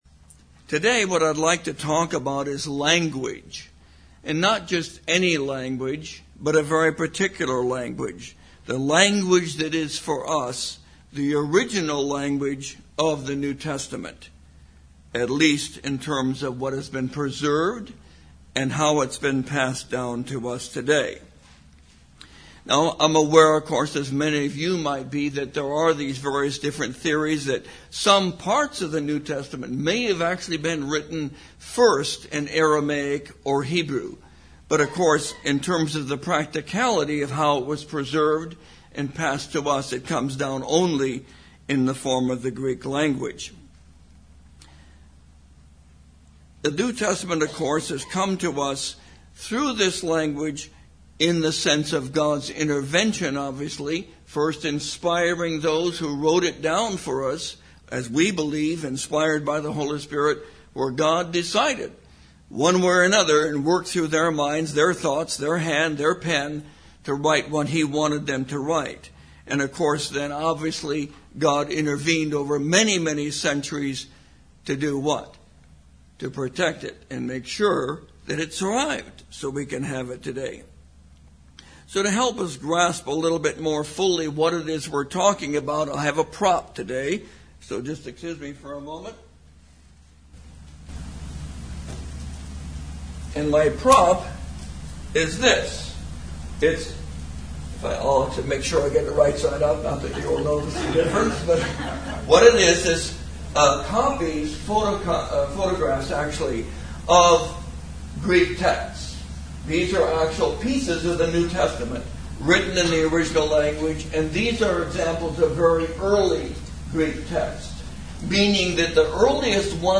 Given in Redlands, CA